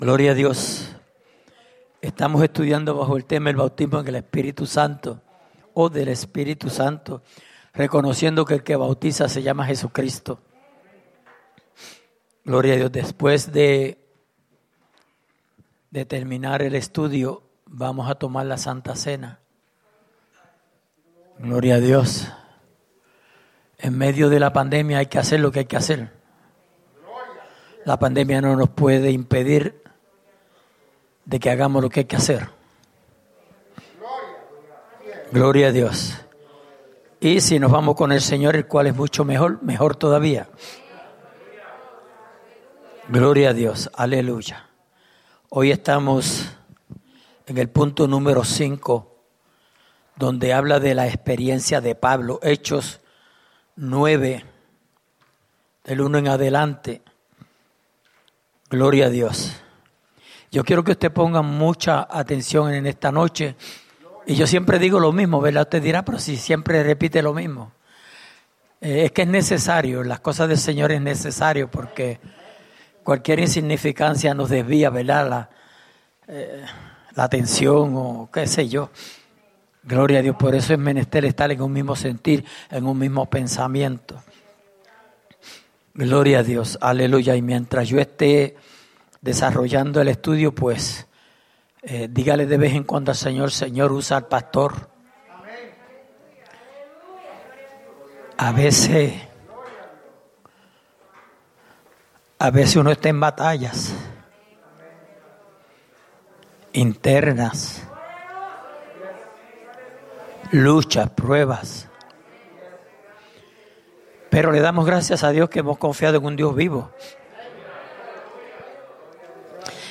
Estudio:El Bautismo en el Espíritu Santo
03/28/2021 @ Souderton, PA